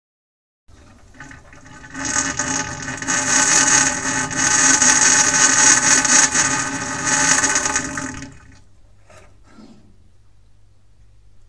Notre chaudière à gaz Chaffoteaux et Maury Niagara 23 FF fait un bruit étrange après quelques secondes de démarrage (cf fichier joint audio). Le voyant 11 "intervention du thermostat de sécurité" s'allume rouge : La chaudière se coupe et plus de chauffage et d'eau chaude.